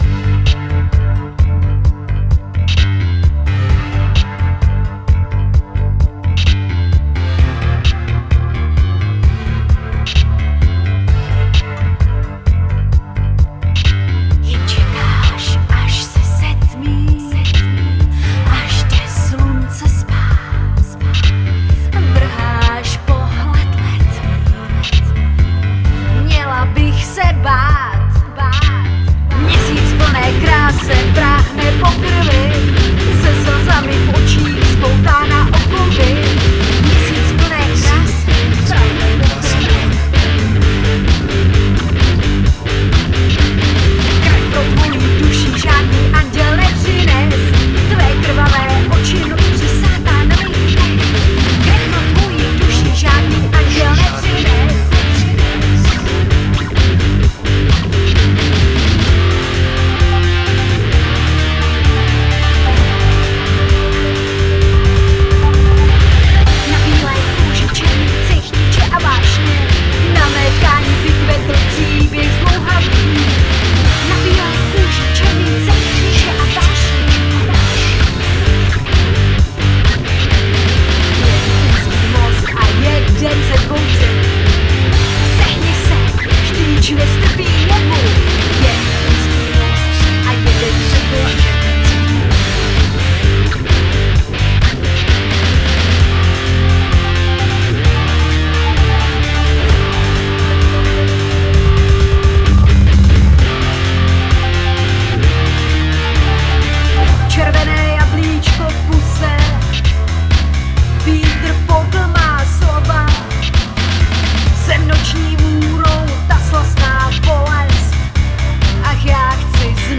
Hudba
Zpěv